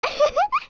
giggling sound effect as fellow Sage Saria.
OOT_Ruto_Laugh.wav